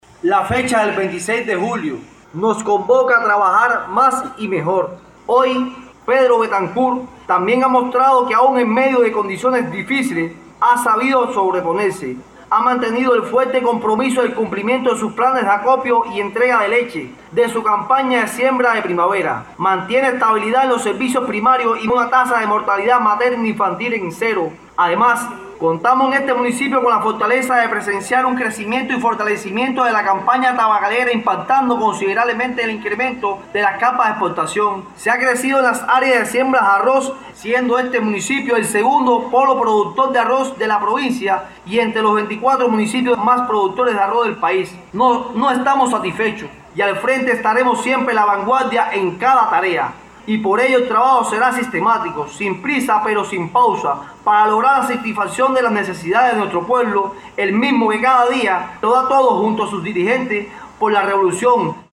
Al aniversario 72 del asalto a los cuarteles Moncada y Carlos Manuel de Céspedes se dedicó el acto político-cultural por el Día de la Rebeldía Nacional, efectuado en la plaza “28 de septiembre” del municipio de Pedro Betancourt
Las palabras centrales del acto estuvieron a cargo de Royslán Cámbara Sosa, primer secretario del Partido en el municipio, quien abordó los principales avances y desafíos del territorio en el contexto socioeconómico actual, al tiempo que resaltó el significado histórico de la efeméride como expresión del espíritu fundacional de la Revolución cubana.